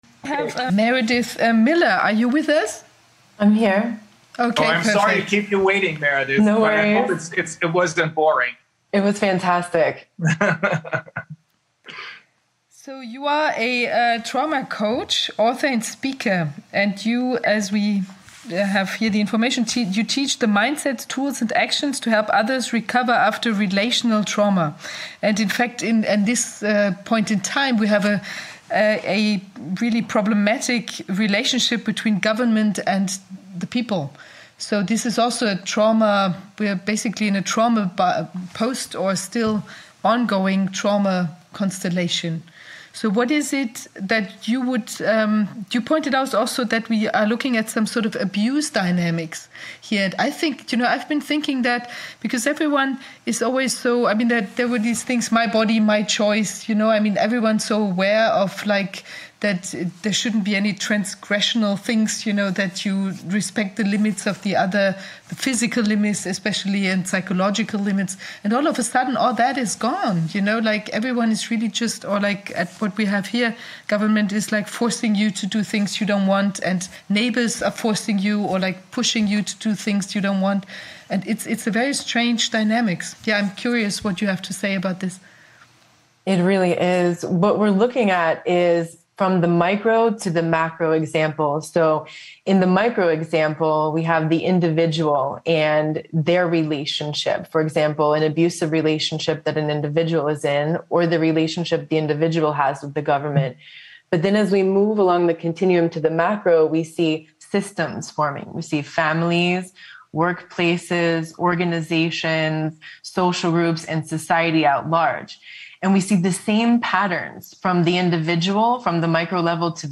מדובר בהסבר נעים ורהוט על מערכת היחסים המתעללת בין הממסד לאזרח.